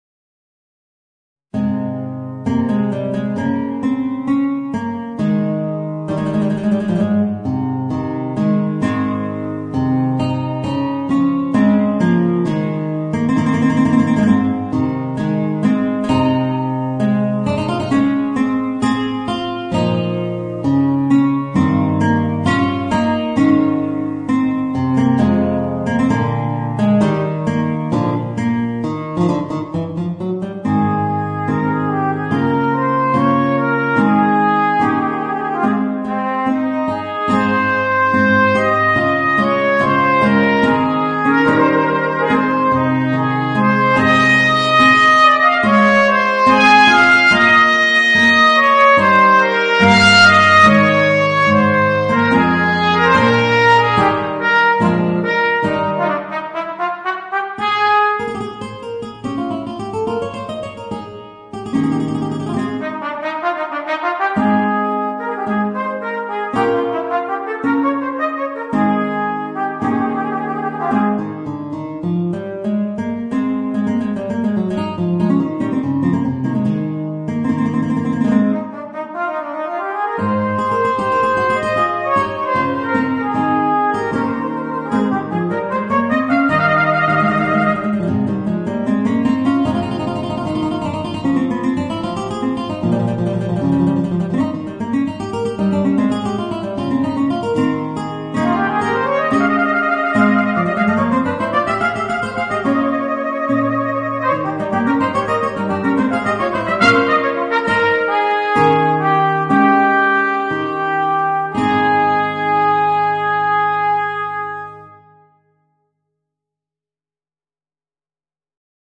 Voicing: Trumpet and Guitar